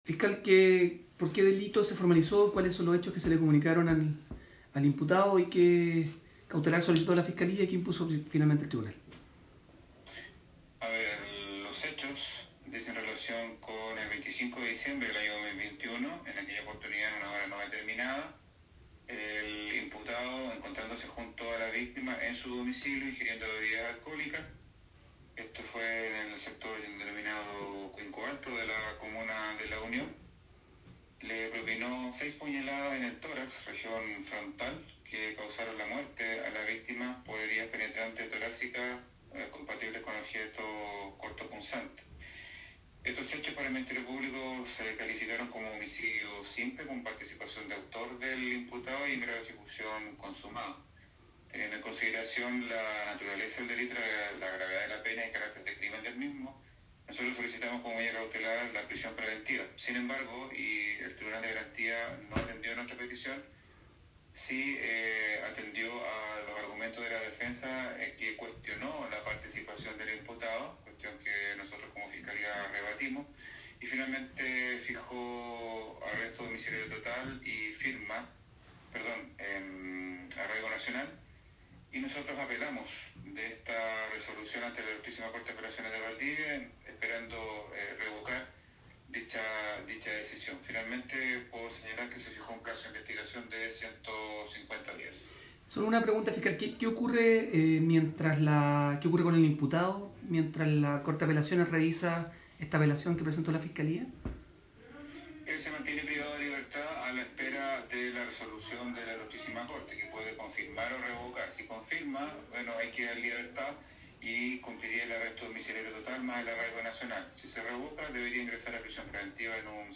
Fiscal Rául Suárez….